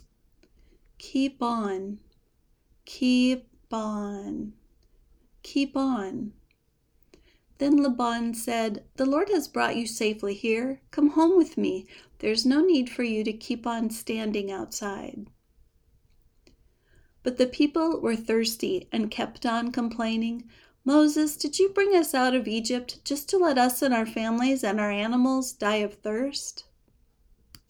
kip ɔn (phrasal verb)